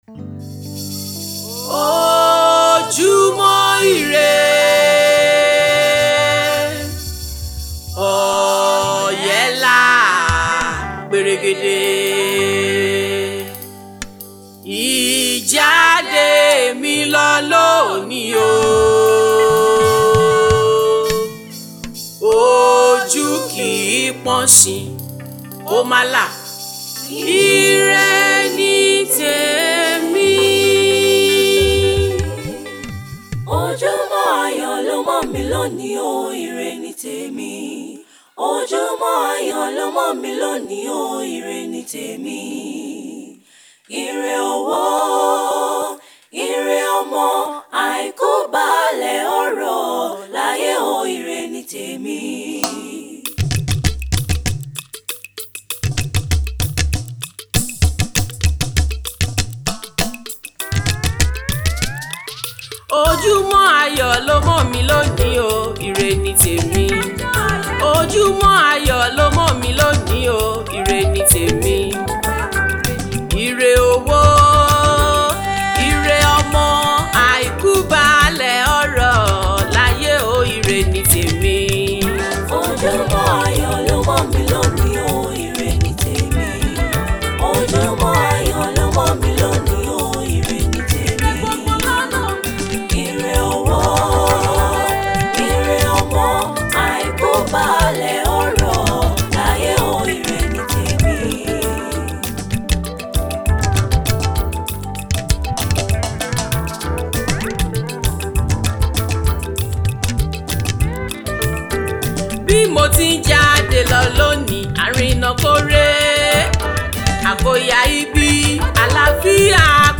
inspirational song